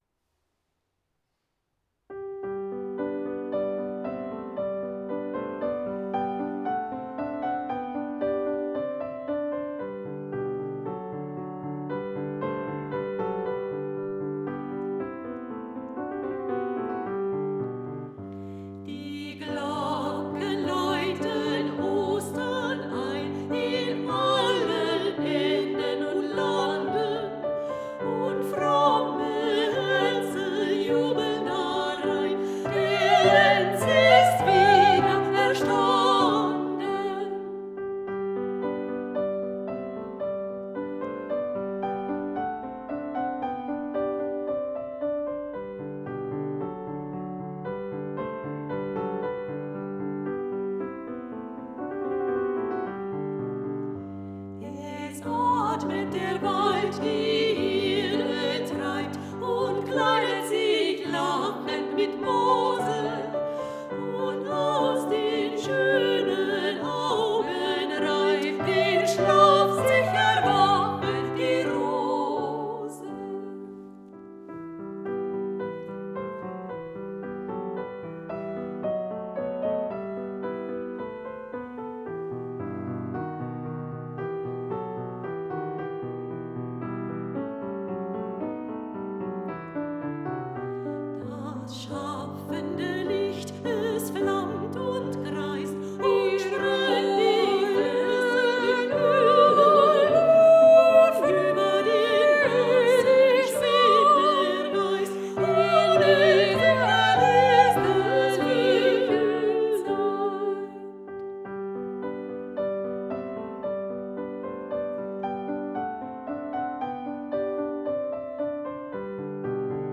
Ein musikalischer Gruß zu Ostern